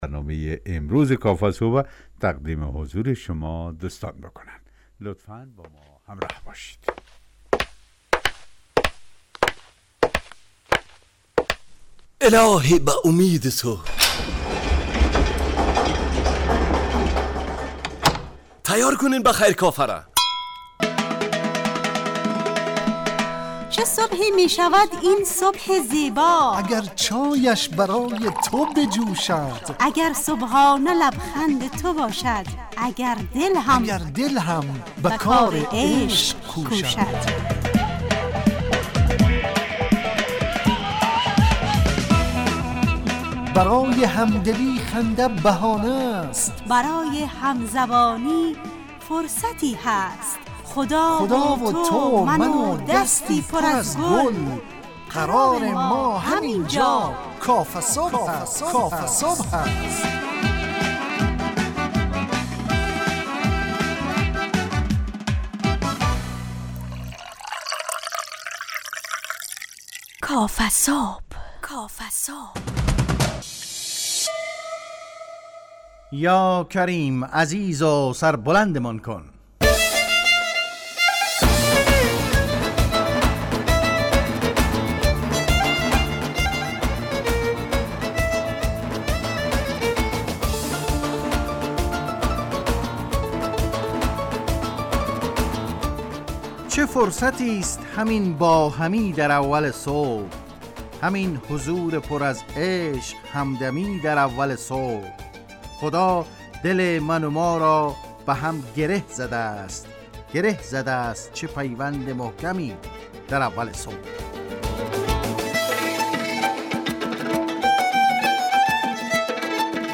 کافه صبح - مجله ی صبحگاهی رادیو دری با هدف ایجاد فضای شاد و پرنشاط صبحگاهی همراه با طرح موضوعات اجتماعی، فرهنگی، اقتصادی جامعه افغانستان همراه با بخش های کارشناسی، نگاهی به سایت ها، گزارش، هواشناسی و صبح جامعه، گپ صبح و صداها و پیام ها شنونده های عزیز